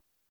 drum-hitwhistle.mp3